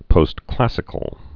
(pōst-klăsĭ-kəl)